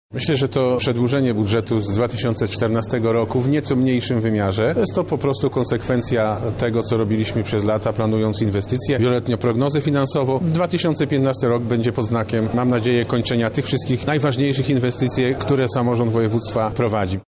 – Między innymi te inwestycje będą kontynuowane w najbliższym roku – tłumaczy Sławomir Sosnowski, Marszałek Województwa Lubelskiego